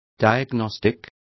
Complete with pronunciation of the translation of diagnostic.